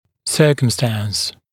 [‘sɜːkəmstæns][‘cё:кэмстэнс]обстоятельство, случай